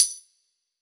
Bp Metal.wav